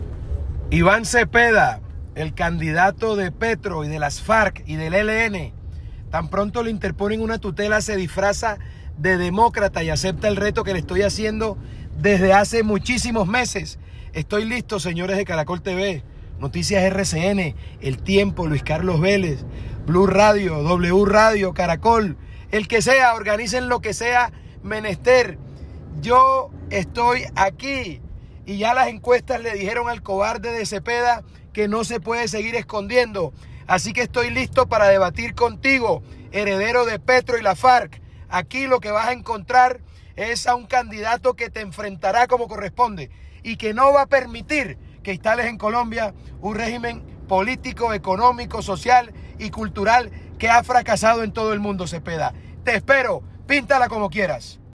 Audio declaración de ABELARDO DE LA ESPRIELLA: